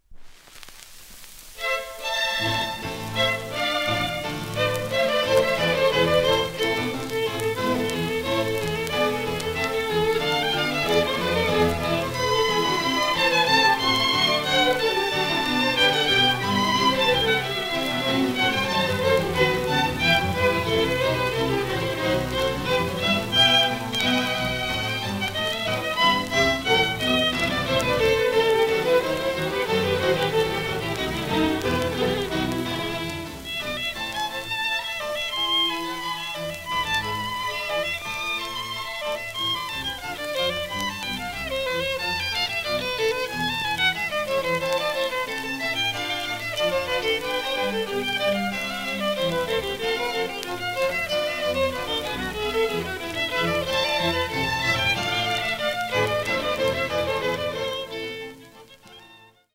1939年ベルリン録音
原盤番号の末尾の「T」は、演奏会場から電話回線で音声信号を録音室に送り原盤を作成したという記号。